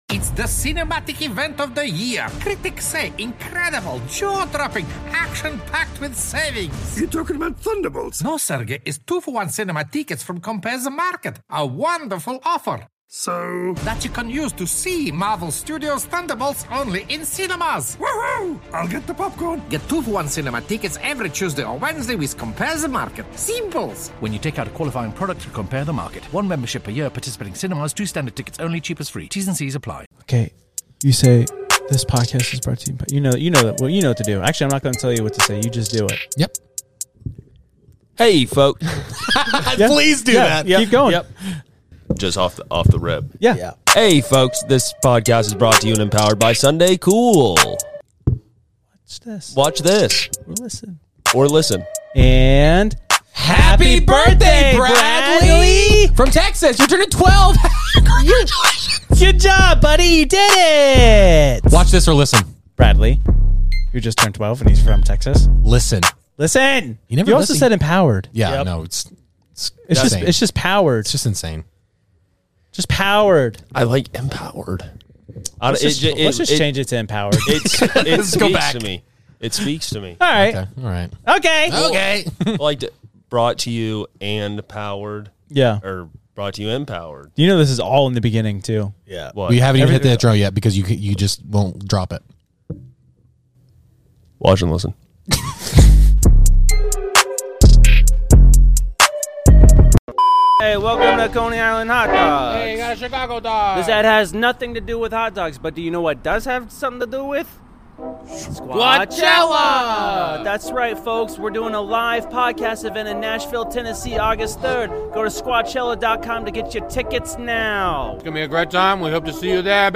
As the NAB crews cracks up over the latest news